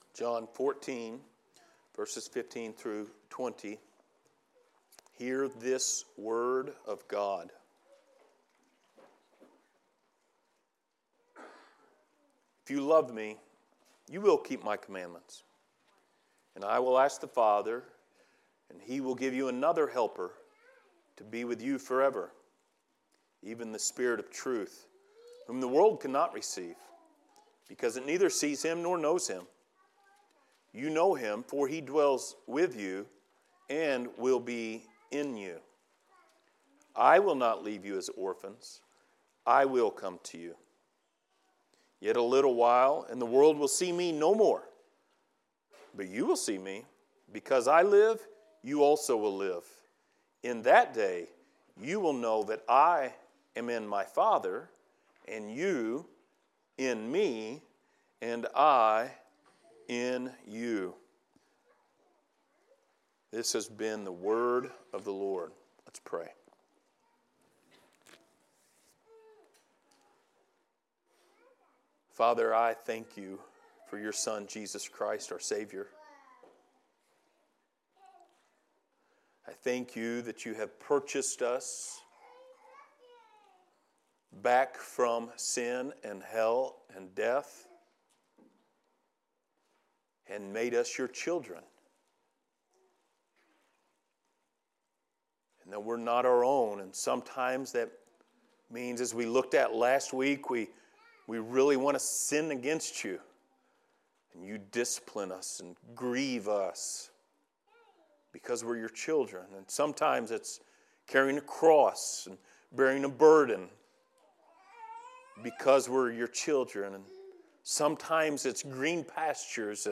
Passage: John 14:15-20 Service Type: Sunday Morning